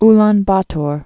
(län bätôr)